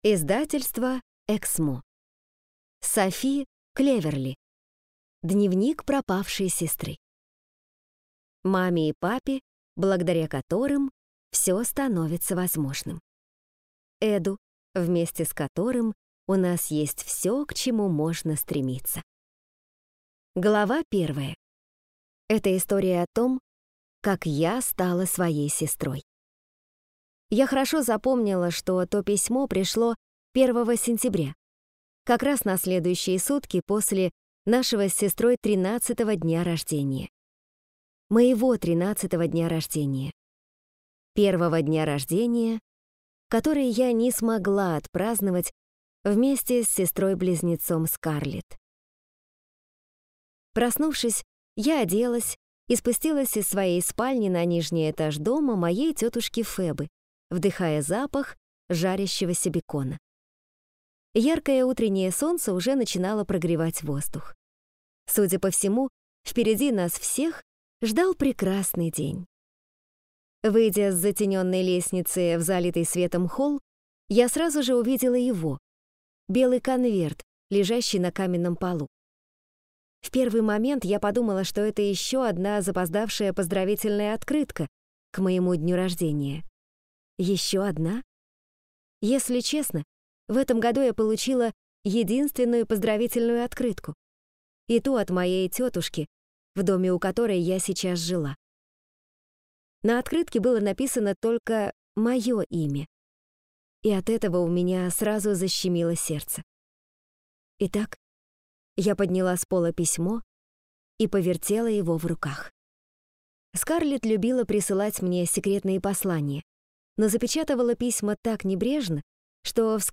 Аудиокнига Дневник пропавшей сестры | Библиотека аудиокниг